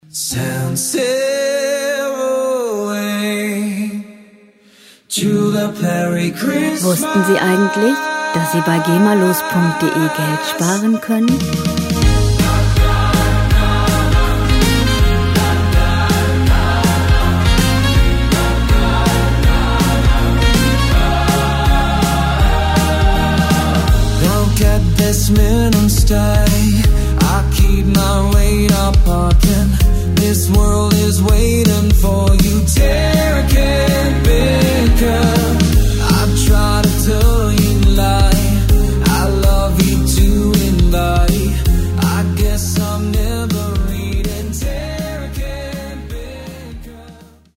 Gemafreie moderne Weihnachtslieder
Musikstil: Christmas Pop
Tempo: 162 bpm
Tonart: As-Dur/C-Dur
Charakter: einladend, unbeschwert